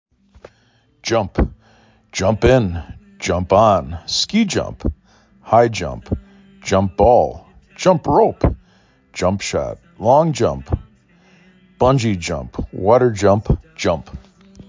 4 Letters, 1 Syllable
4 Phonemes
j uh m p